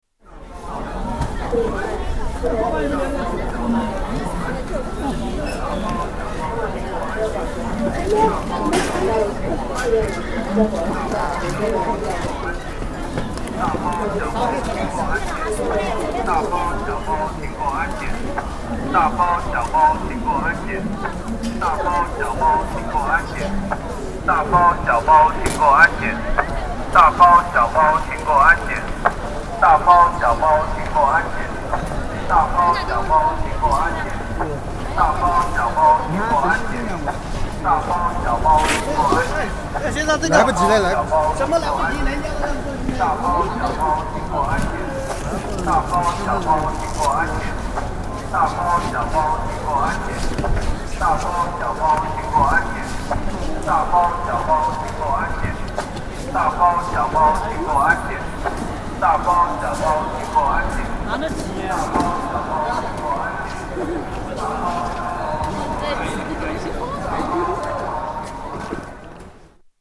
Hangzhou railway station